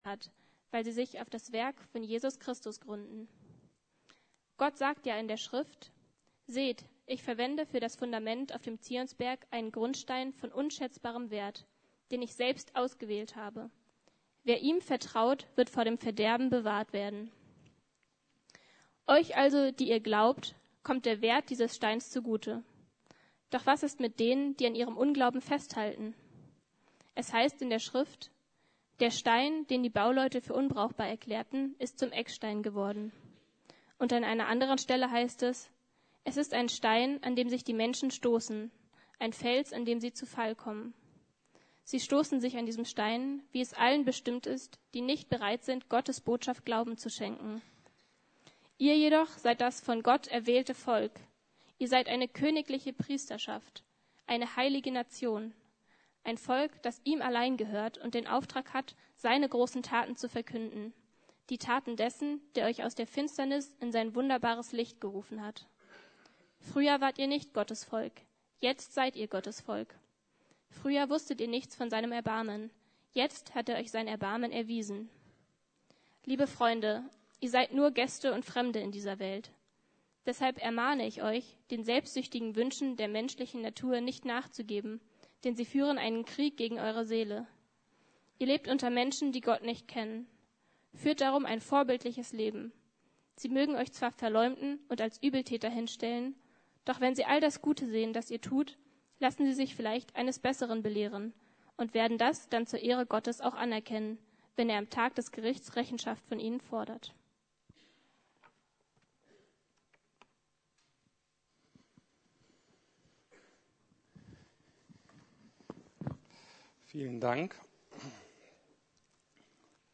Wie Gott die Welt regiert: DIE KIRCHE ~ Predigten der LUKAS GEMEINDE Podcast